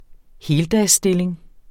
Udtale [ ˈheːldas- ]